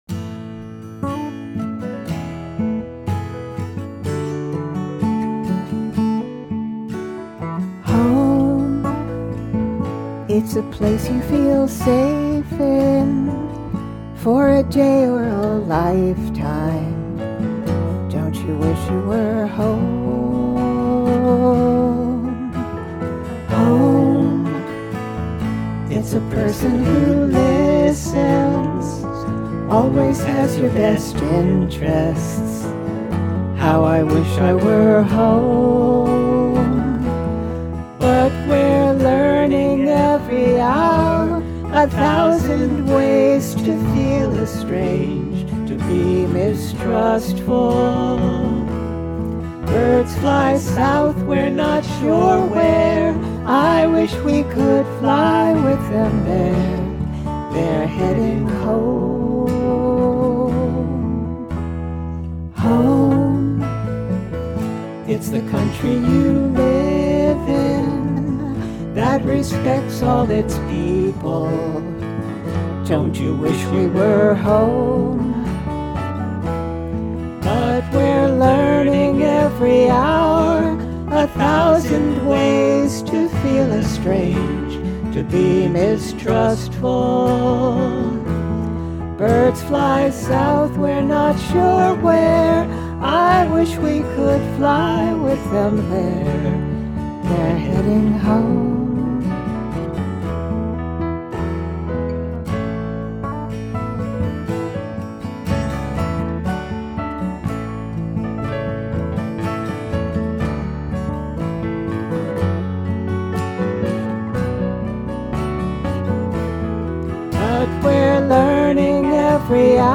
Song list (with links to "studio" versions)
Set 1 (with acoustic guitar)